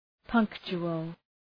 Προφορά
{‘pʌŋktʃʋəl}